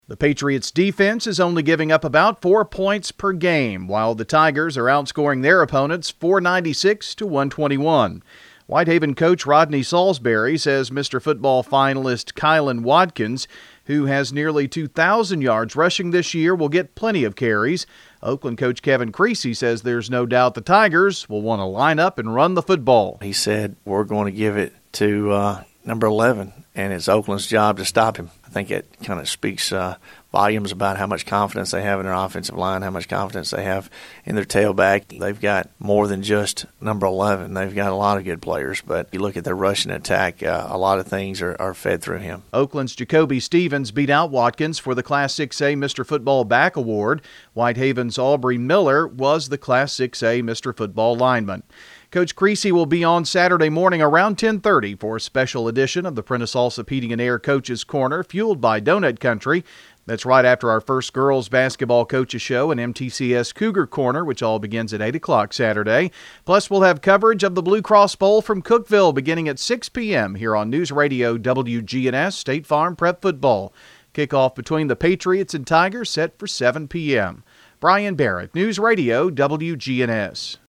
The Oakland Patriots are seeking their fourth state football championship Saturday as they battle the Whitehaven Tigers out of Memphis. NewsRadio WGNS'